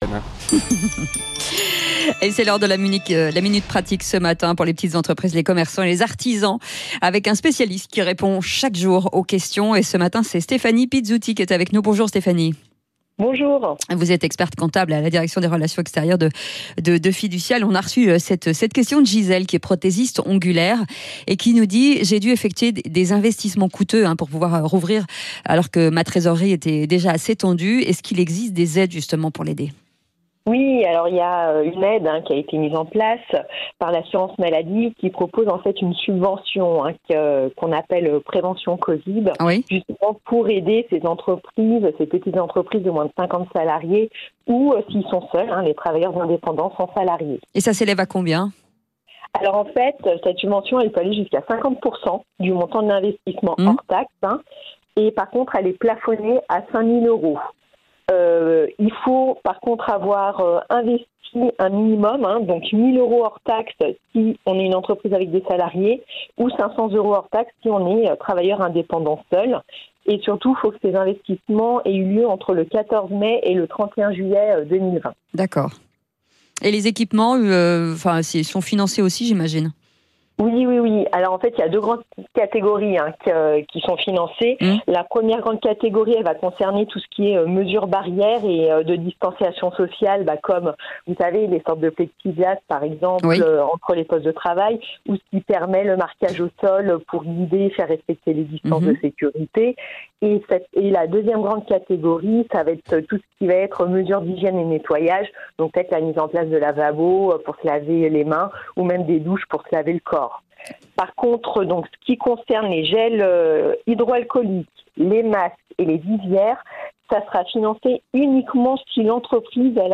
La minute pratique - A 9 heures, chaque jour dans le Grand Matin Sud Radio, des spécialistes Fiducial vous répondent.